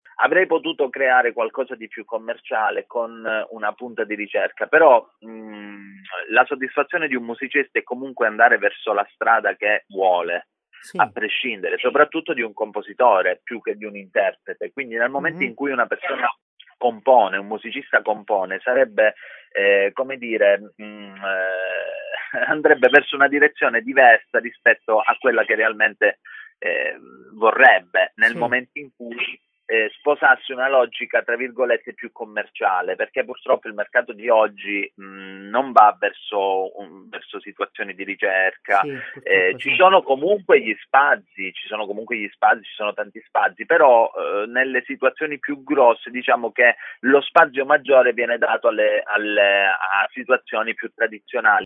La telefonata